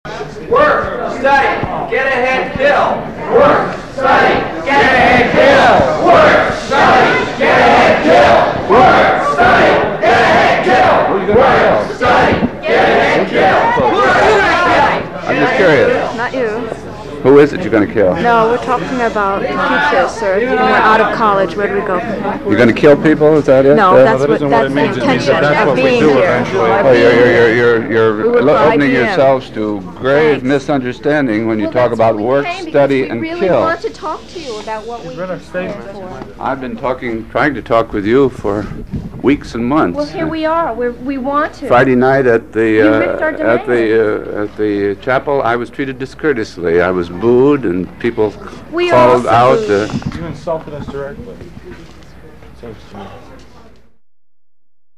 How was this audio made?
More of the WOBC tape, February 20, 1969.